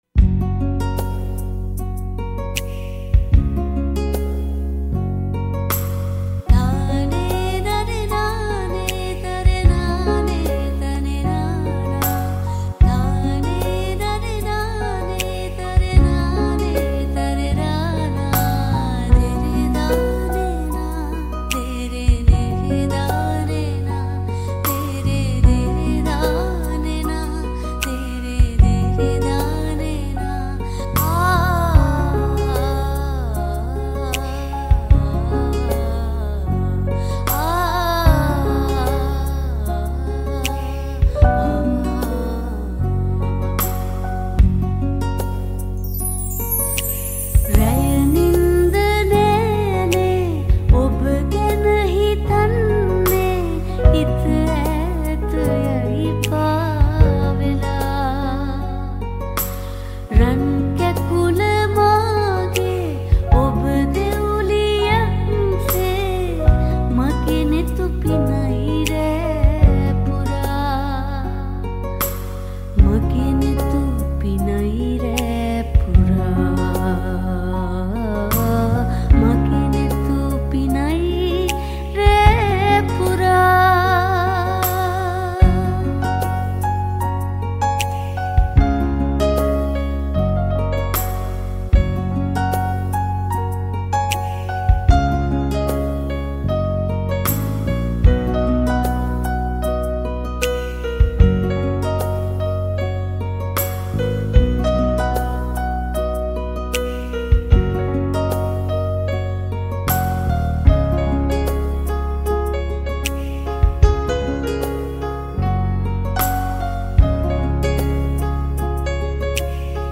Keys